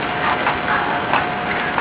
This was recorded in the main section of the theatre, I just asked the ghost to come out and play with us and this voice was recorded "having fun"